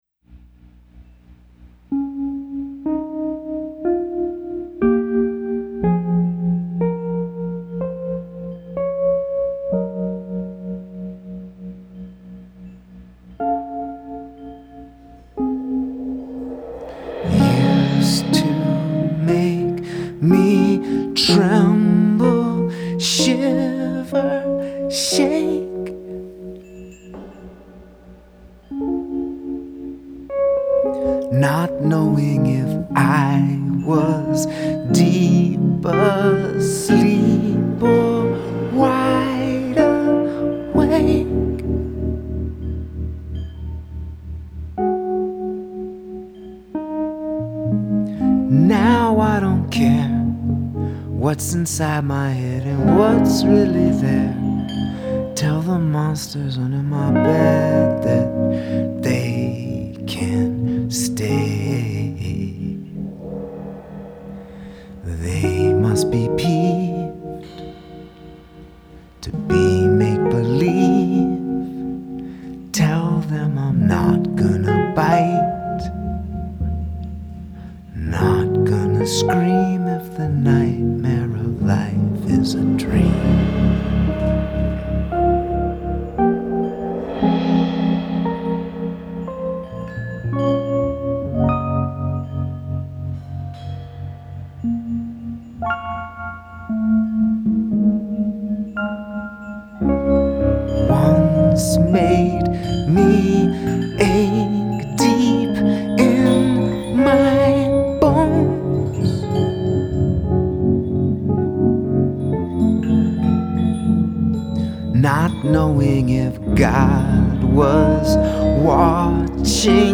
mixed at sauce farm studio, New York